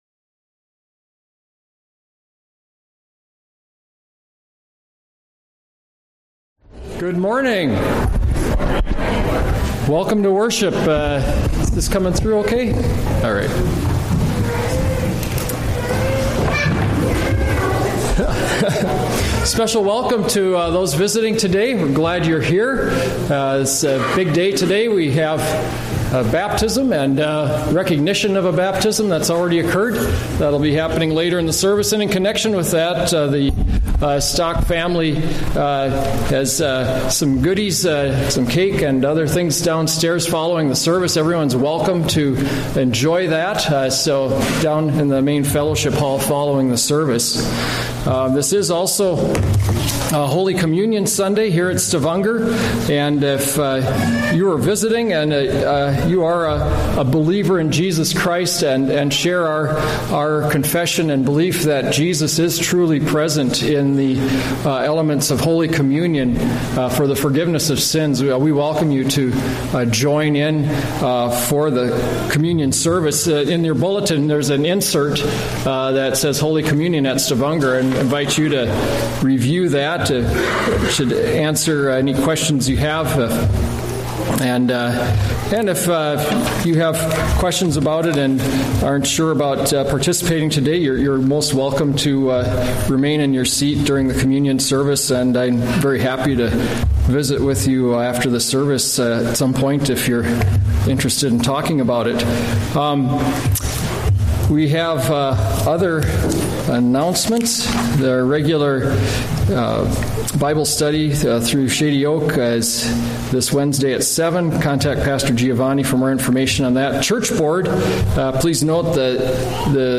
A message from the series "Sunday Worship."
From Series: "Sunday Worship"